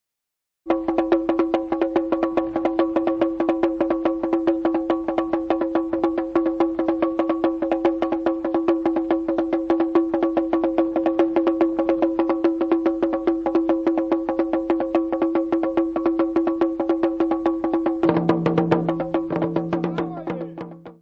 Tambours ingoma.